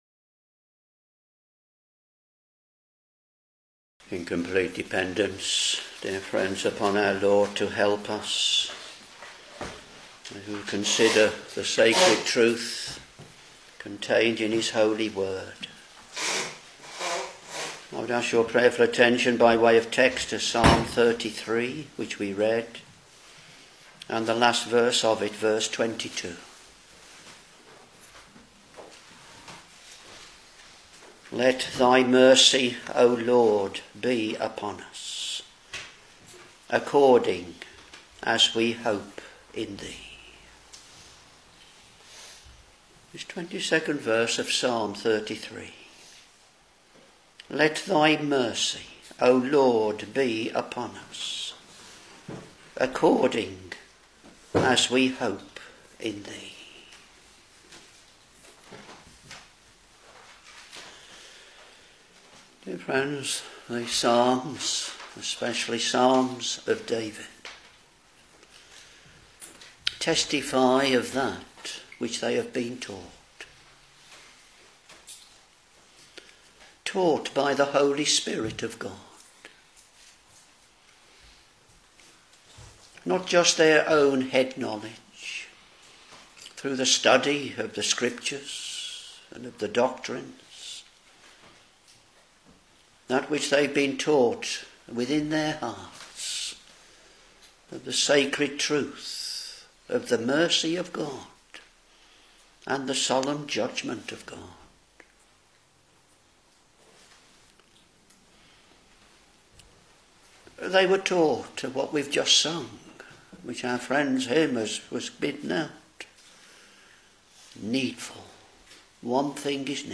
Sermons Psalm 33 v.22 Let thy mercy, O LORD, be upon us, according as we hope in thee.